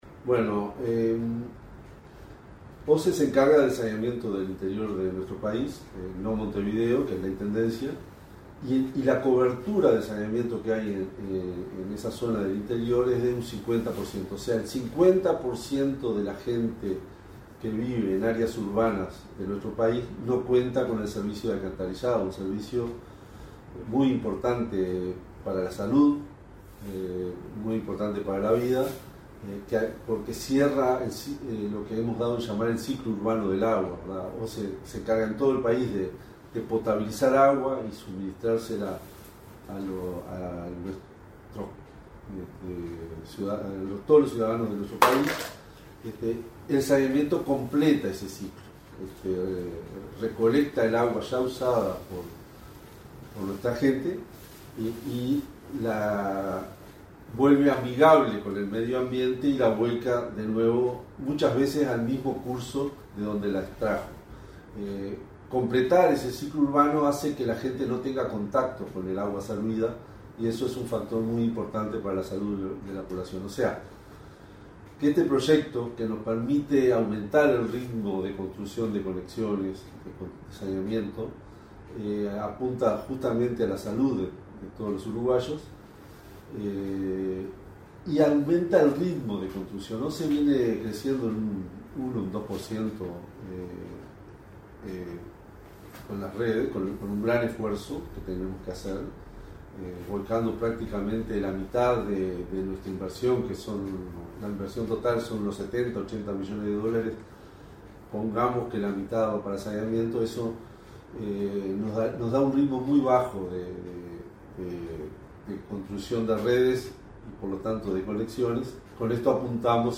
Entrevista al presidente de OSE, Raúl Montero